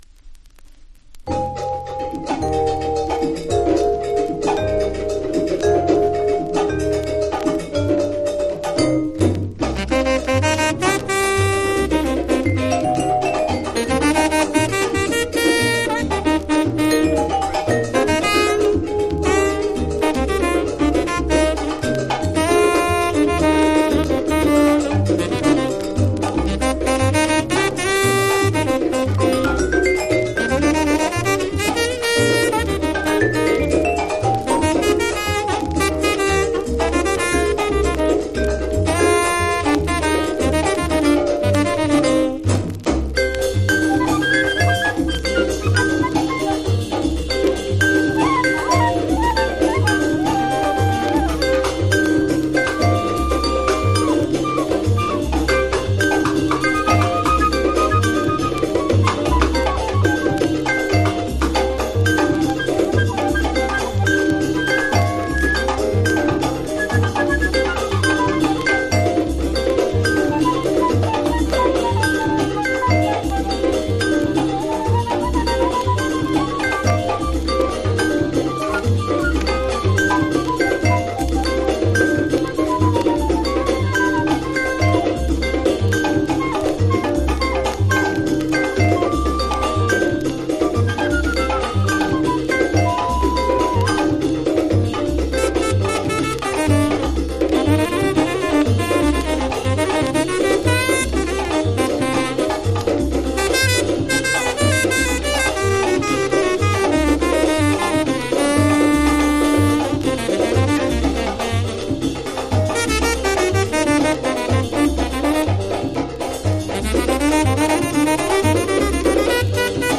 Vibe
（プレス・小傷によりチリ、プチ…